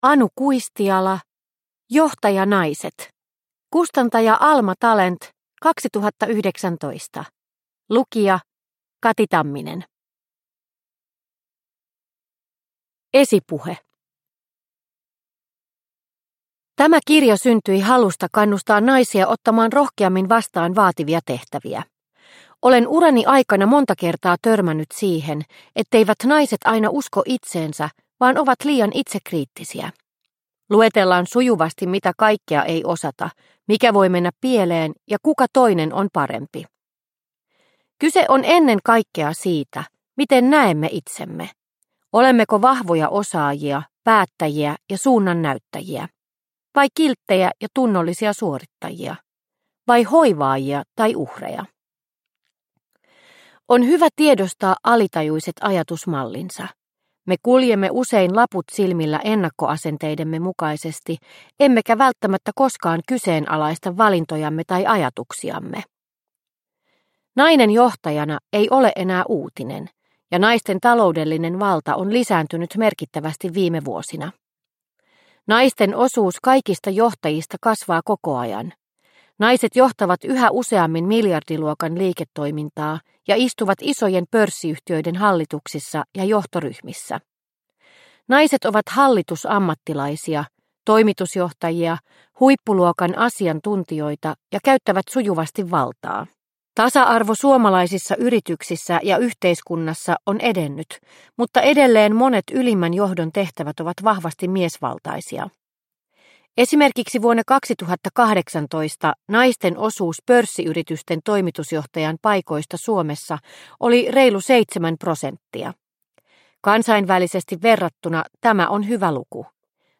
Johtajanaiset – Ljudbok – Laddas ner